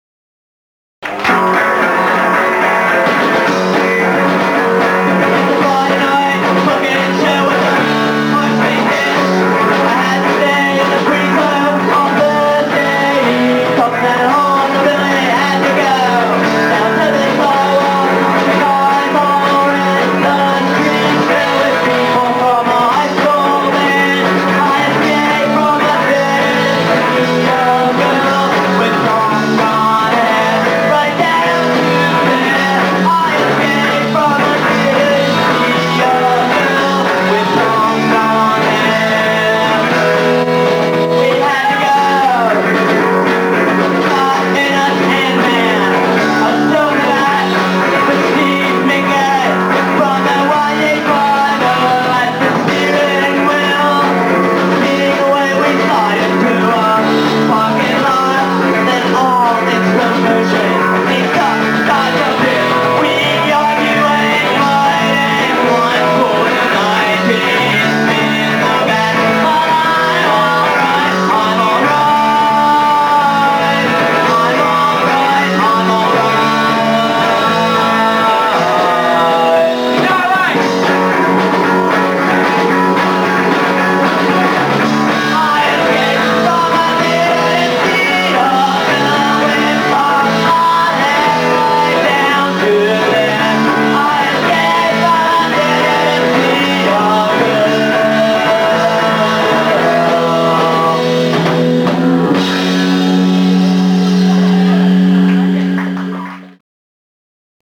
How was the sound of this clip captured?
Elk’s Lodge Atlantic City 1985?